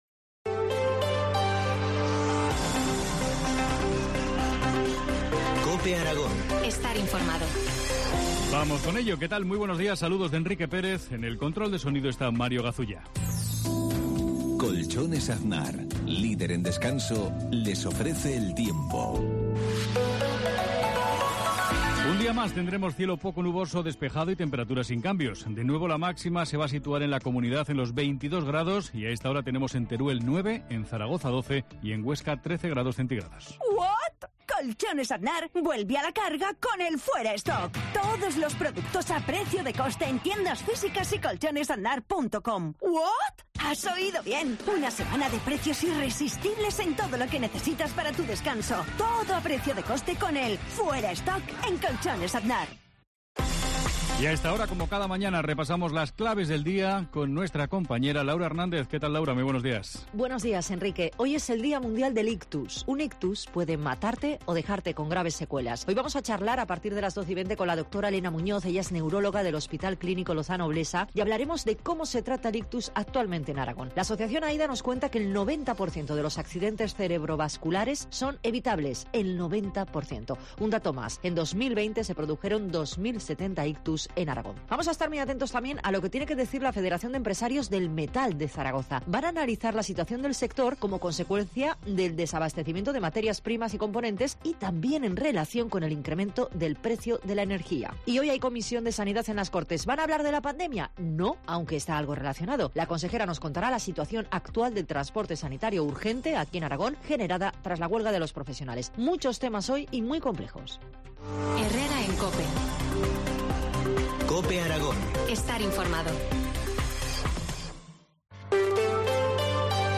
La Mañana en COPE Huesca - Informativo local